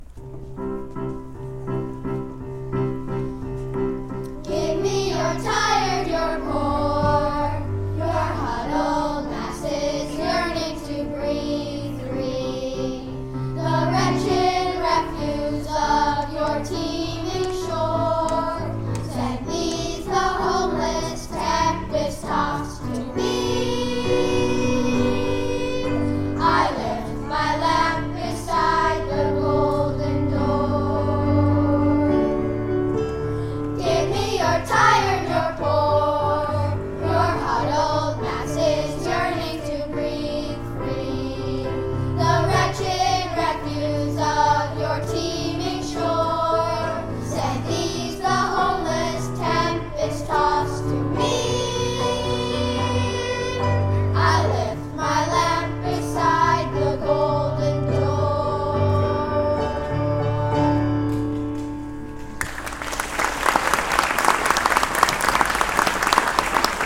The whole class then sings the optimistic, bright version of “Give Me Your Tired”.